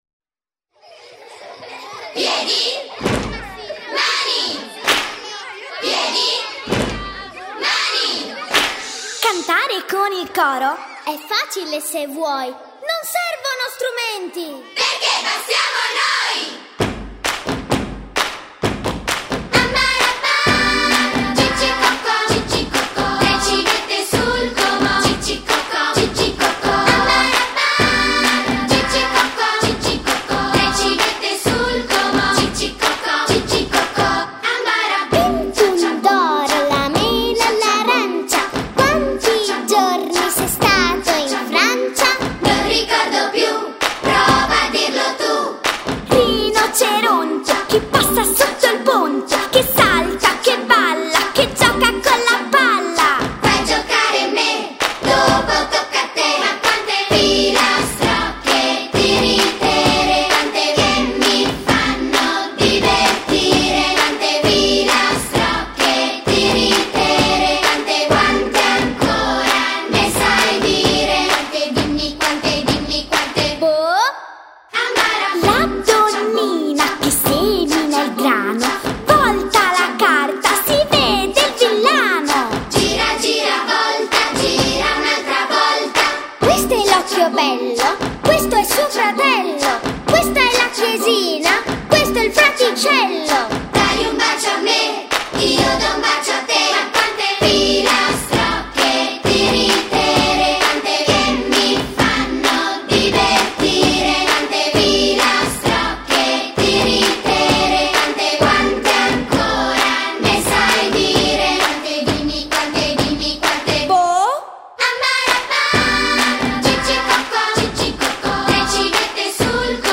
[1/6/2010]【超龄儿童们 节日快乐】意大利童声（天下声音） 激动社区，陪你一起慢慢变老！